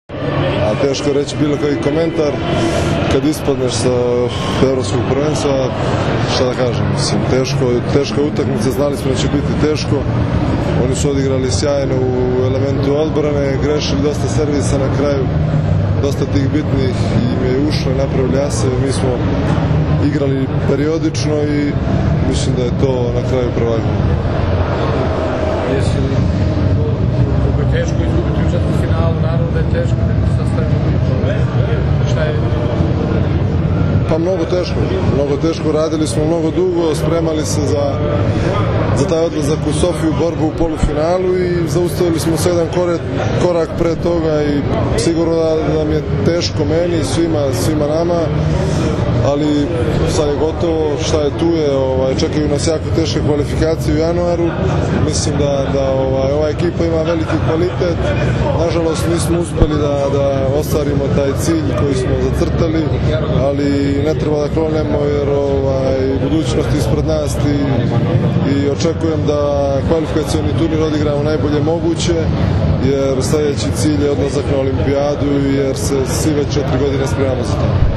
IZJAVA NEMANJE PETRIĆA